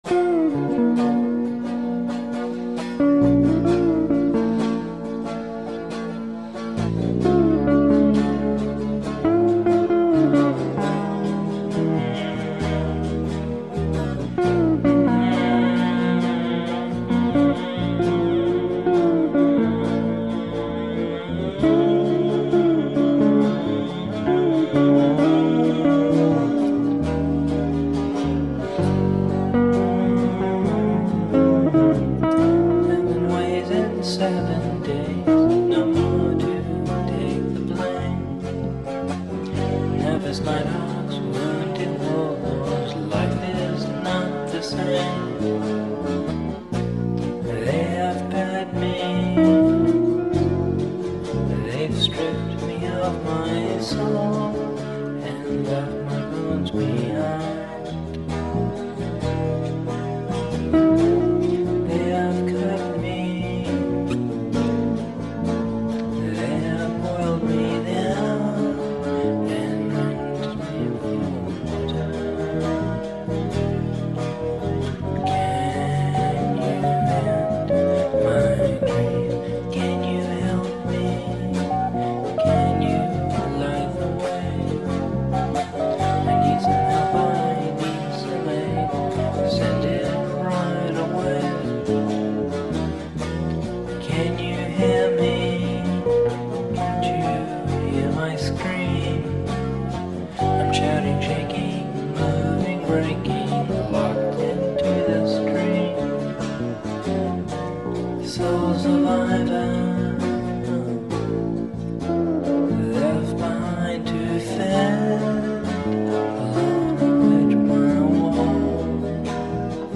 Solo Vocal Stuff:
Dream (1990) The first guitar lick wrote the song.
I decided to write a part that started by "unbending" the first note.
Recorded on 4 track cassette, and remixed to digital through the Mackie in 2001.